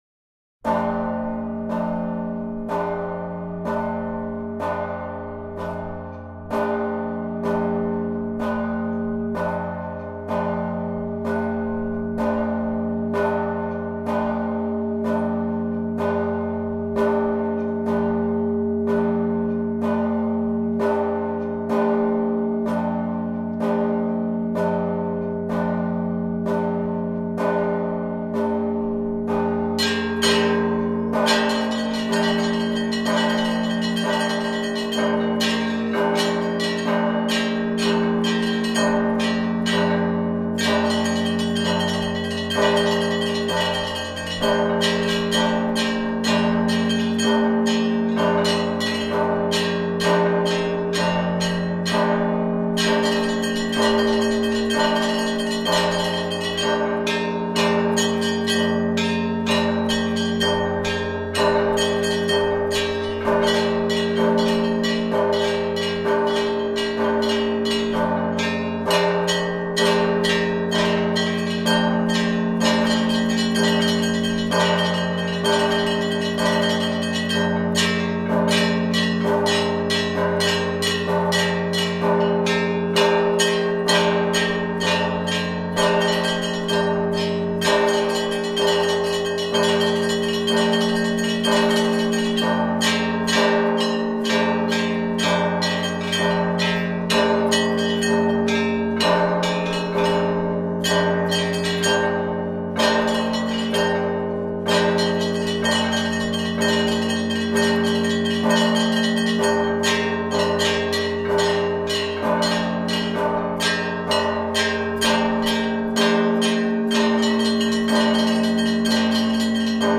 10_Blagovest_Prazdnichnyj_I_Provodnoj_Zvon.mp3